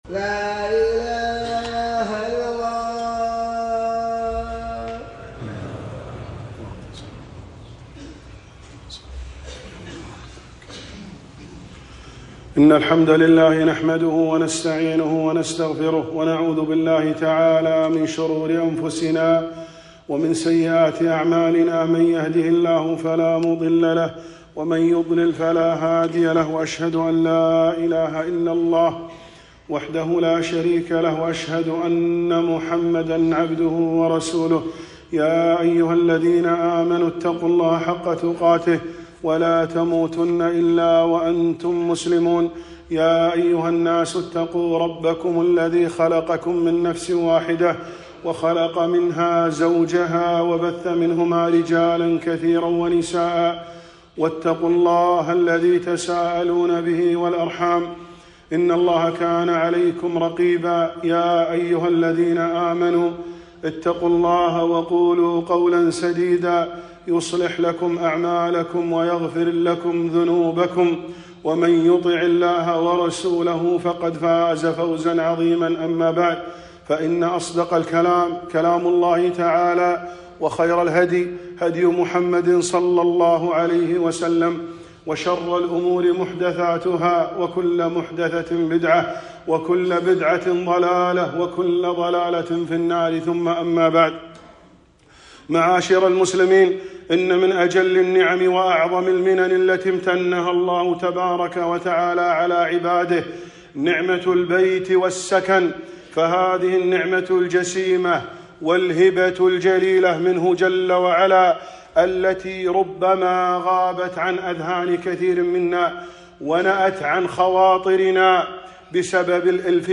خطبة - نعمة المأوى والمسكن - دروس الكويت